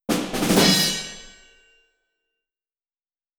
level_complete_03.wav